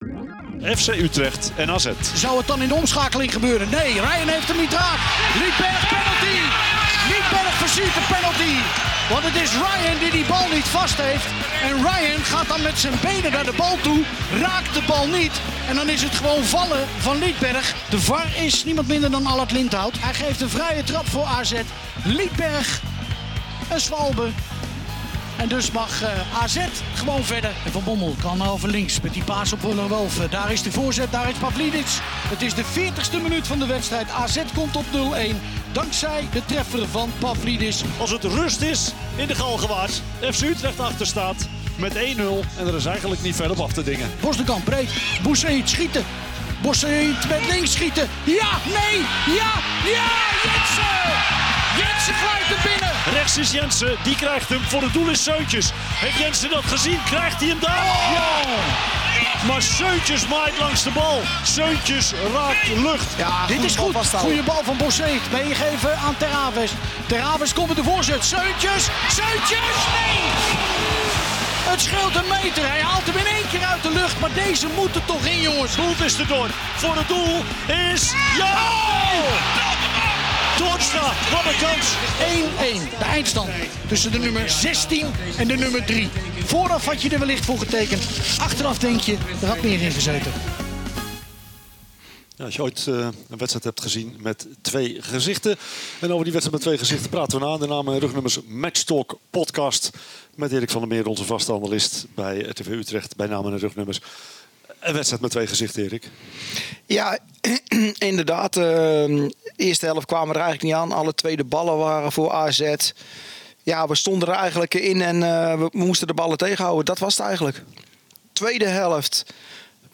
vanuit Stadion Galgenwaard terug op het gelijkspel.